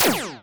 sci-fi_weapon_laser_small_01.wav